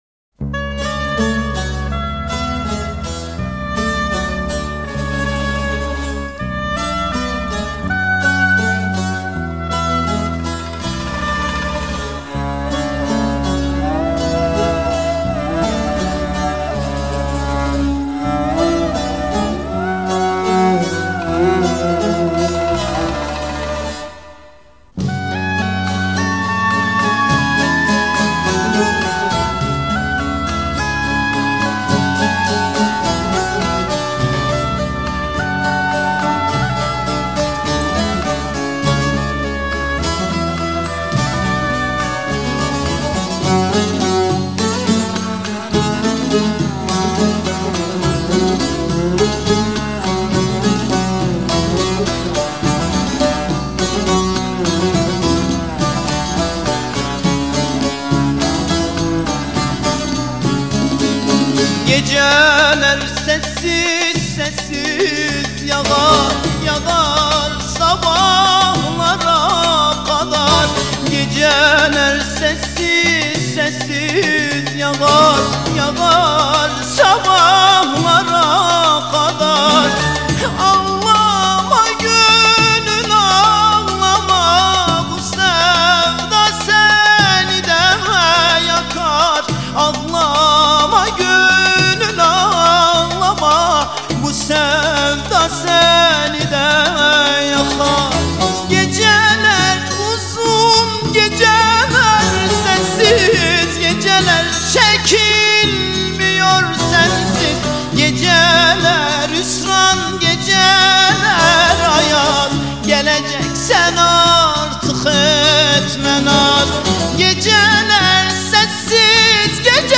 آهنگ ترکیه ای آهنگ غمگین ترکیه ای آهنگ نوستالژی ترکیه ای
این ترانه زیبا در سال ۱۹۸۸ توسط این خواننده محبوب خوانده شده